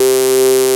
CATOLEADC3.wav